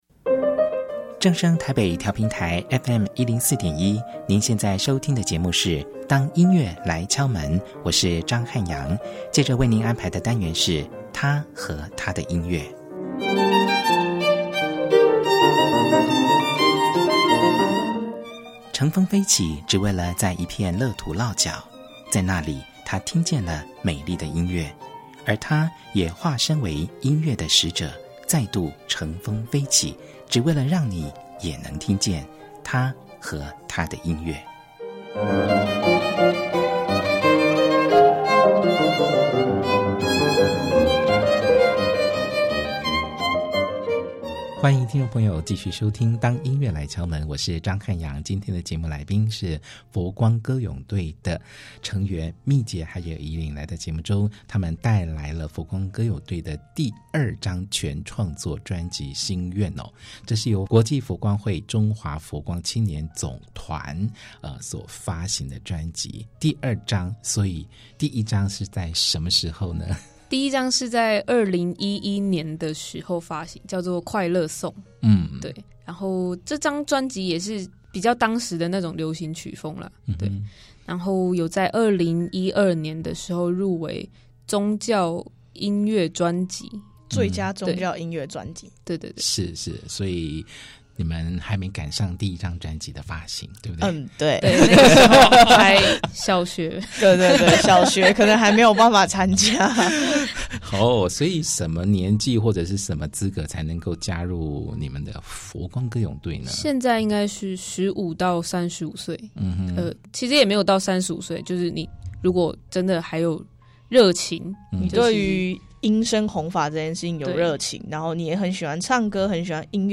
百分百透明度的清新嗓音，深入詮釋各種多元的流行曲風，時而深沉呢喃，時而嘹亮高亢，平衡且和諧。